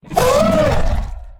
hurt3.ogg